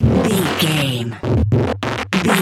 Short musical SFX for videos and games.,
Sound Effects
Epic / Action
Fast paced
Ionian/Major
energetic
funky